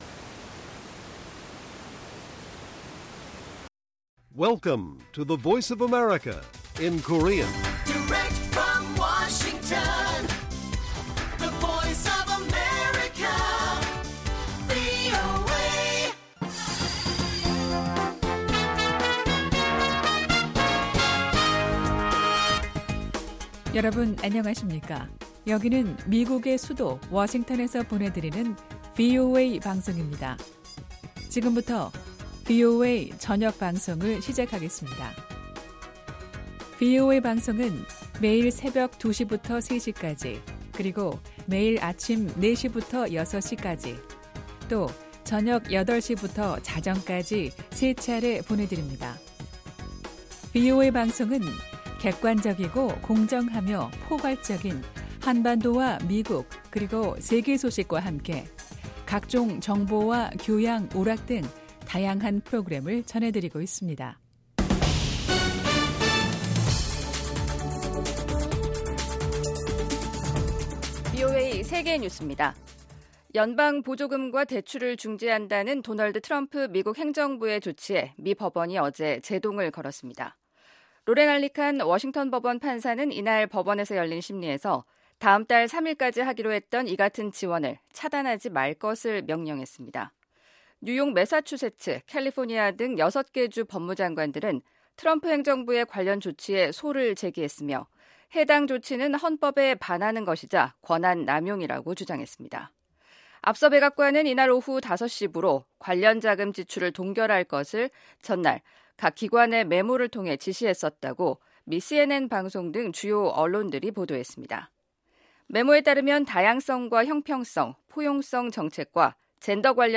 VOA 한국어 간판 뉴스 프로그램 '뉴스 투데이', 2025년 1월 29일 1부 방송입니다. 미국 백악관은 ‘북한의 완전한 비핵화’가 여전히 트럼프 행정부의 목표라고 확인했습니다. 도널드 트럼프 대통령이 미국의 차세대 미사일 방어 시스템 개발을 촉구하는 행정명령에 서명했습니다. 김정은 북한 국무위원장은 핵 대응 태세를 무한히 강화한다는 입장을 거듭 밝혔습니다.